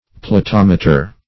platometer - definition of platometer - synonyms, pronunciation, spelling from Free Dictionary Search Result for " platometer" : The Collaborative International Dictionary of English v.0.48: Platometer \Pla*tom"e*ter\, n. [Gr.